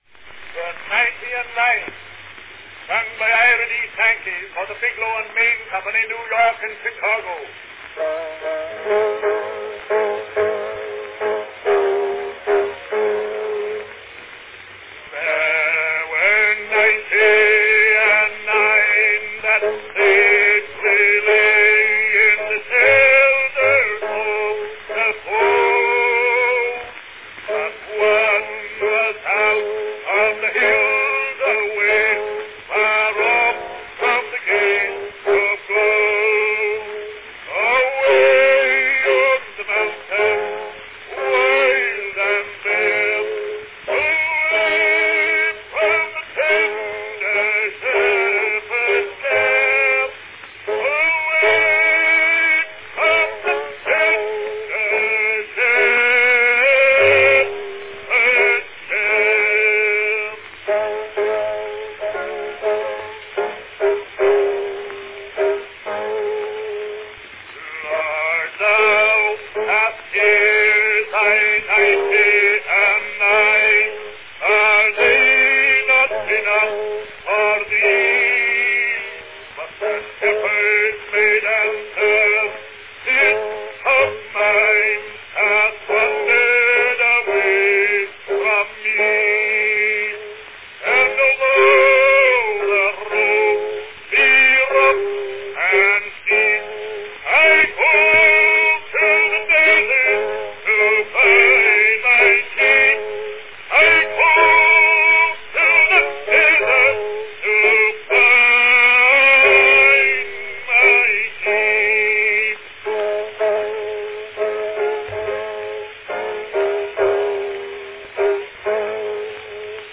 From 1898, a rare and historic recording by Ira D. Sankey singing his first and favorite hymn, The Ninety and Nine.
Category Baritone
Performed by Ira D. Sankey
Announcement "The Ninety and Nine, sung by Ira D. Sankey, for the Biglow & Main Company, New York and Chicago."
He was primarily known as a fine baritone hymn singer.
It was recorded later in his life at a time his voice was showing signs of age.   The recording is announced by Sankey, and he plays the piano accompaniment.
The Ninety and Nine brown wax cylinder record.